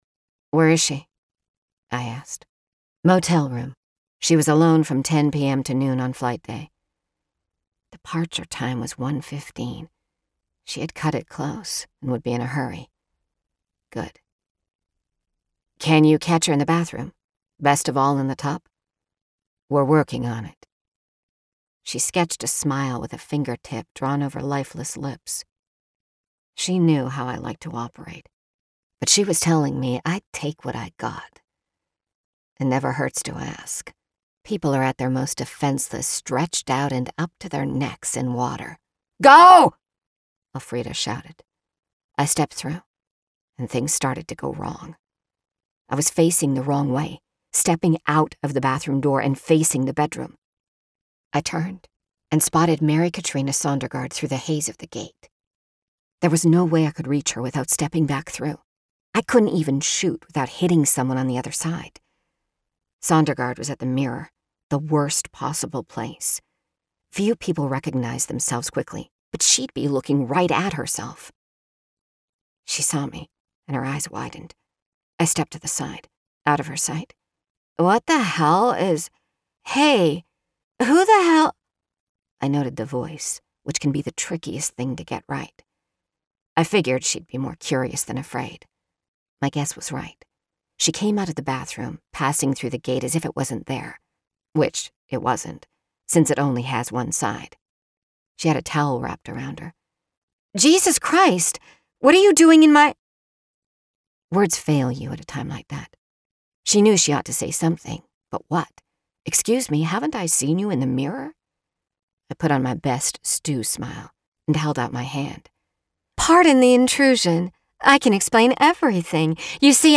AUDIOBOOK  SAMPLES
Science Fiction / Noir |   First Person     |  Female / Female Dialogue